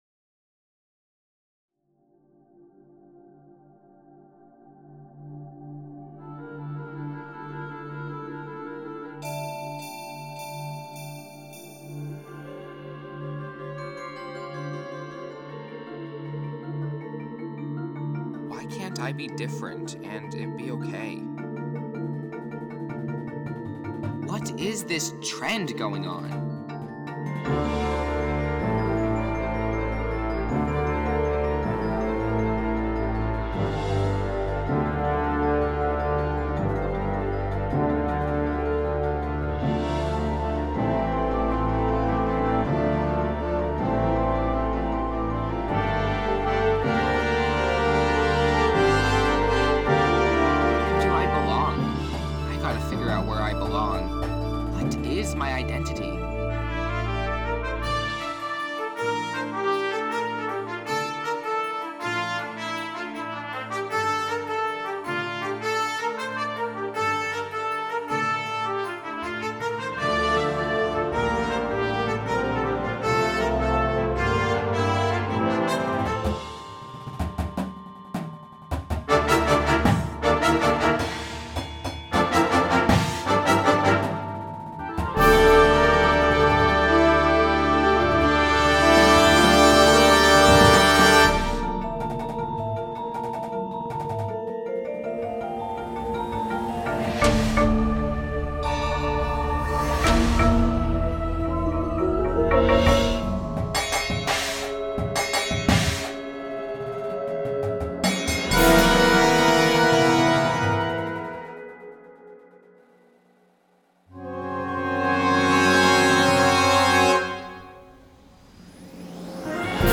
Winds
Percussion